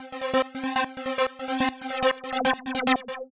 Index of /musicradar/uk-garage-samples/142bpm Lines n Loops/Synths
GA_SacherPad142C-03.wav